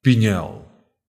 Pinhel (Portuguese pronunciation: [piˈɲɛl]